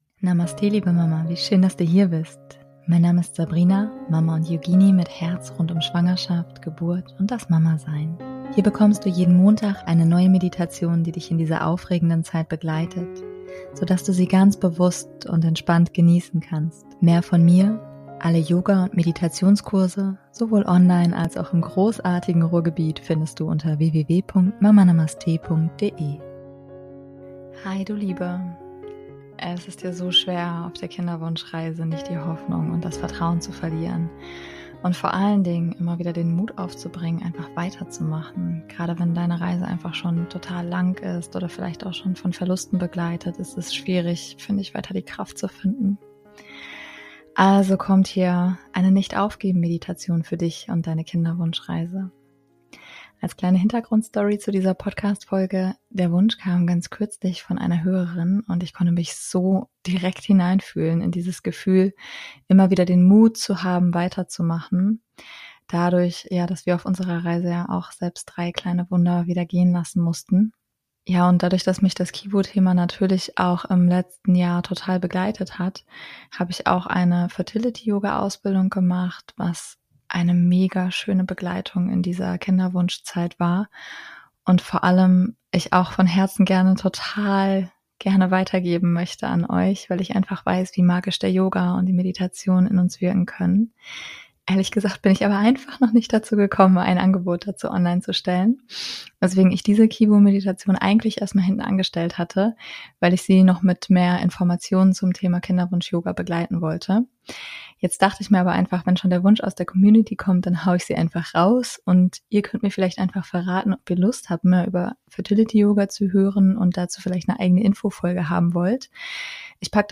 Gerade, wenn deine Reise einfach schon lang ist und vielleicht sogar auch schon von Verlusten begleitet, ist es schwierig weiter Kraft zu finden. Also kommt hier eine Nicht Aufgeben Meditation für dich und deine Kinderwunsch Reise.